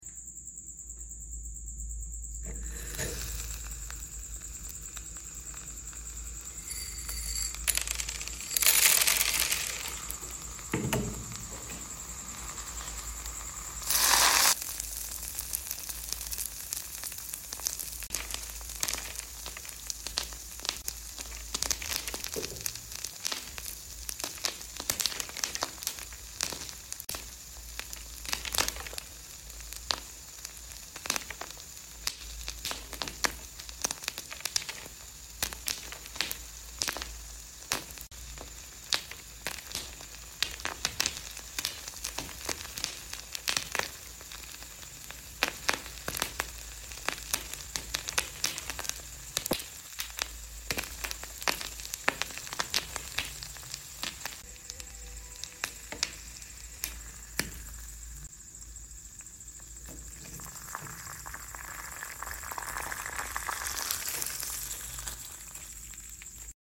Red Hot Ball Vs Popcorn Sound Effects Free Download